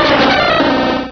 Cri de Roigada dans Pokémon Rubis et Saphir.
Cri_0199_RS.ogg